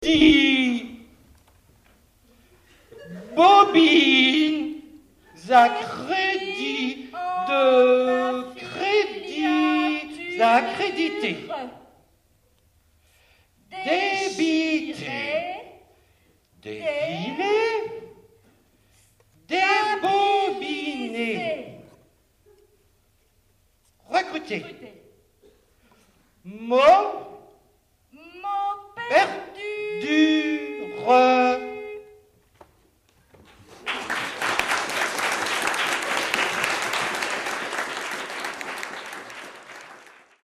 Au Bout de la langue - Le Triangle, Rennes les 26 et 28 septembre 2002 Un duo pétaradant nous balance un bon sang de bois de barouf dada de premier choix.